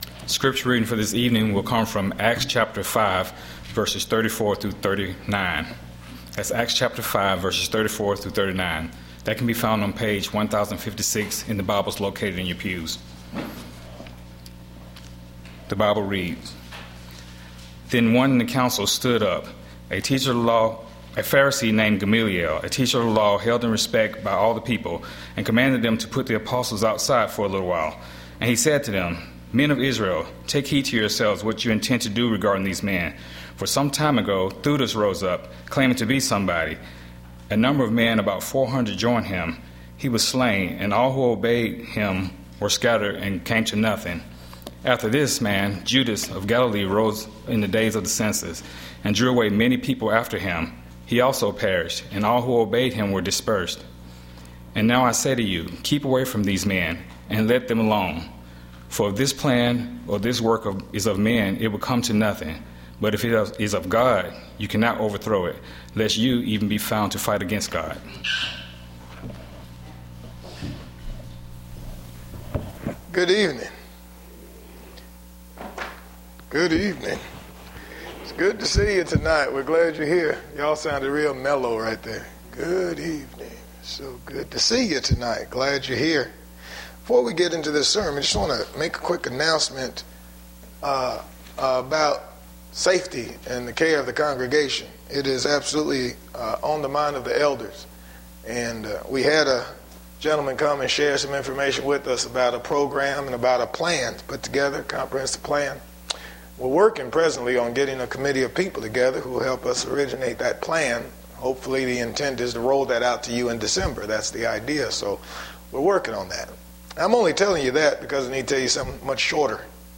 PM Worship